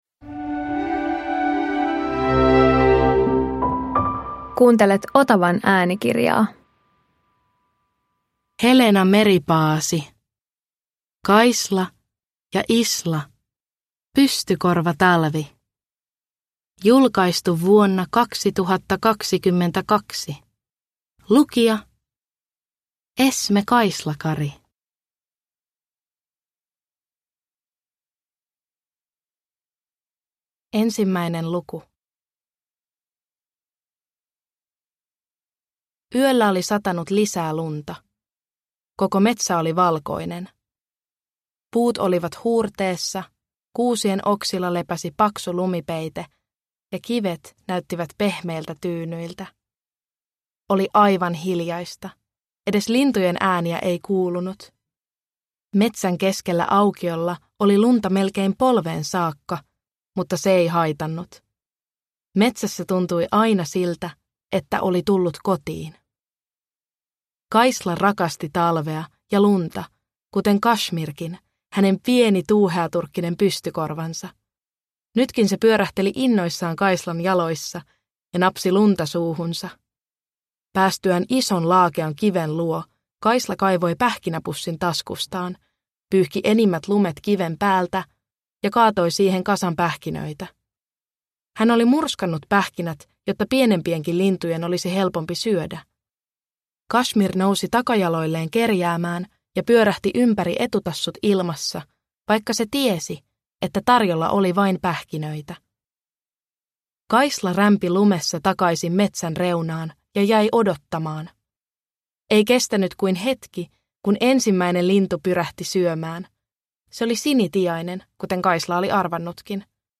Kaisla ja Isla - Pystykorvatalvi – Ljudbok – Laddas ner